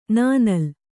♪ nānal